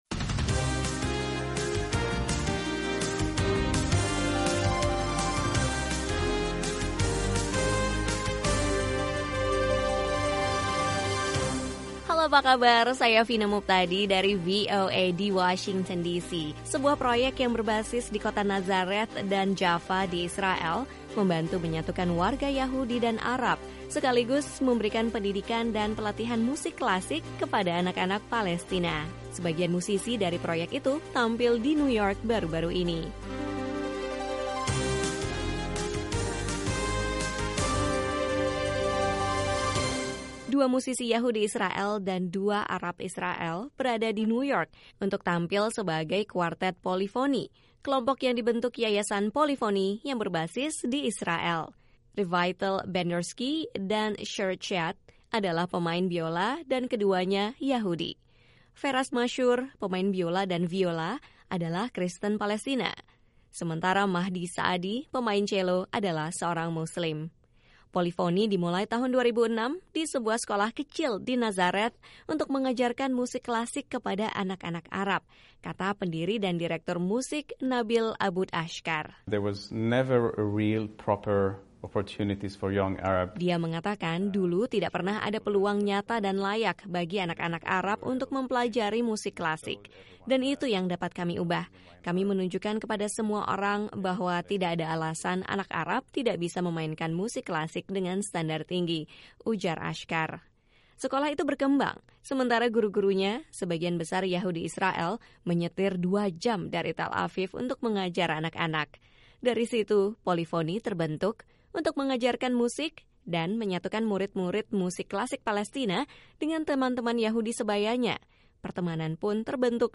Sebuah proyek yang berbasis di kota Nazareth dan Jaffa di Israel membantu menyatukan warga Yahudi dan Arab, sekaligus memberikan pendidikan dan pelatihan musik klasik kepada anak-anak Palestina. Sebagian musisi dari proyek itu tampil di New York baru-baru ini.